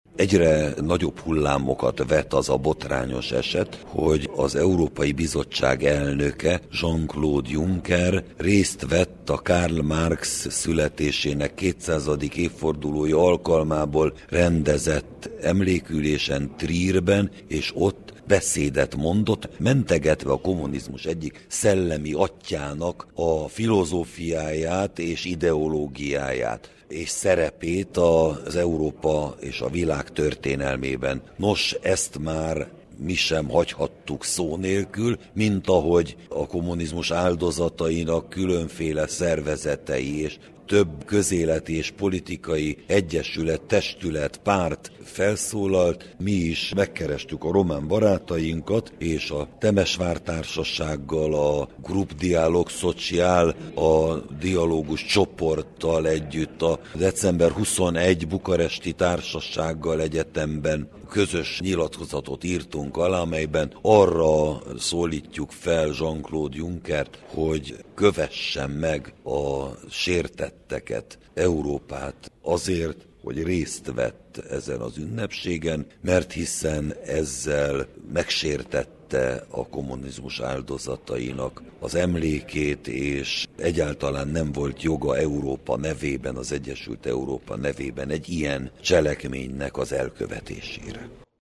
A temesvári emlékbizottság elnöke, Tőkés László tegnapi, marosvásárhelyi sajtótájékoztatóján is említést tett a tiltakozásról: